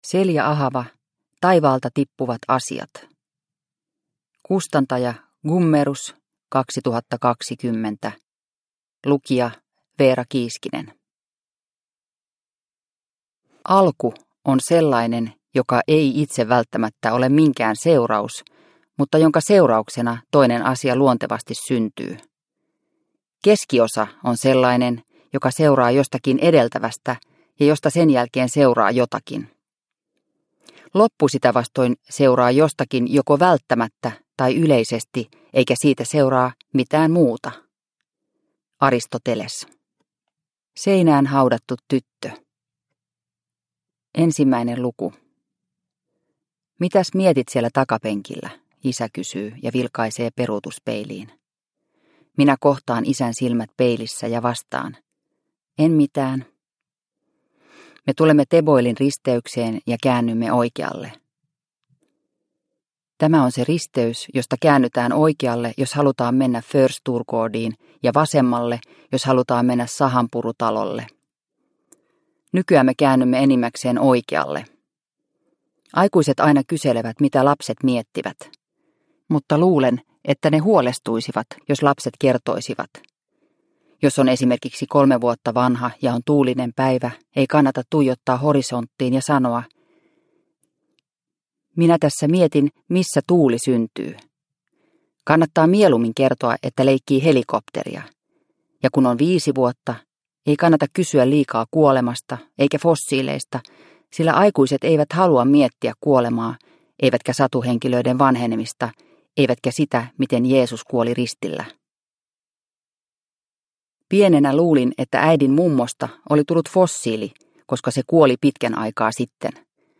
Taivaalta tippuvat asiat – Ljudbok – Laddas ner